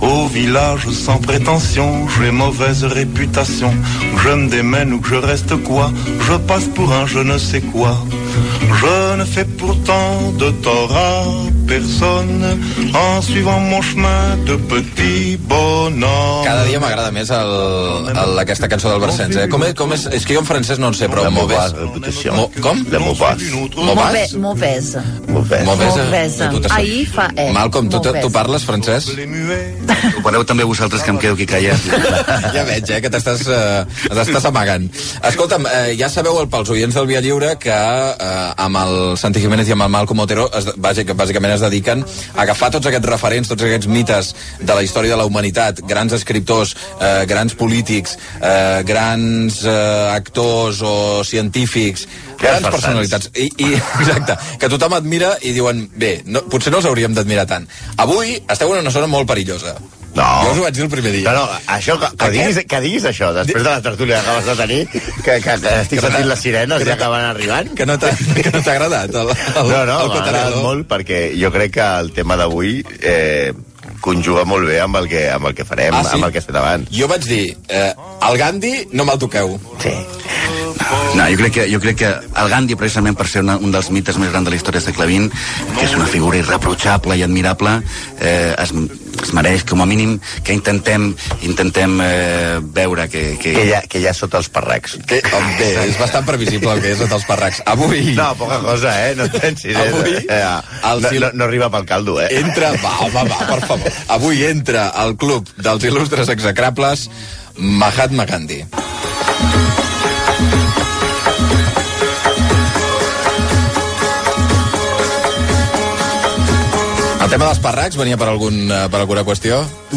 Segona edició de la secció "Els il·lustres execables" dedicada a Mohandas Gandhi Gènere radiofònic Entreteniment